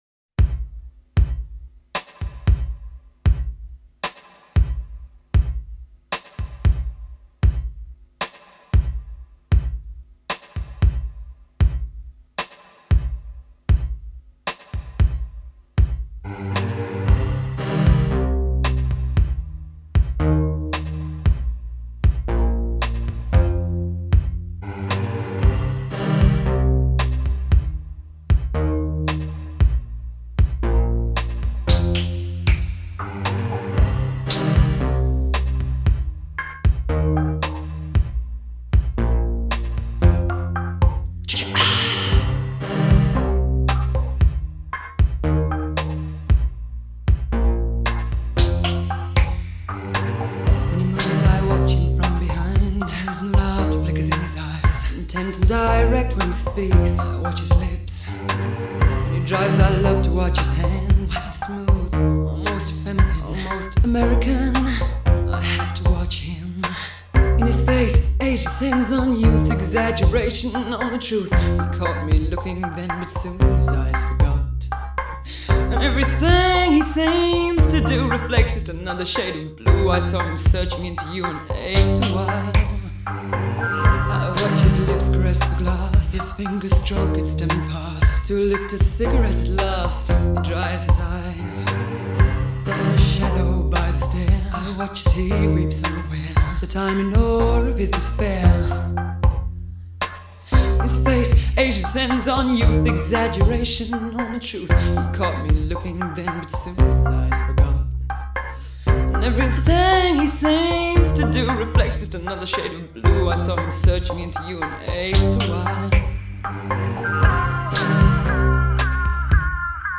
synthpop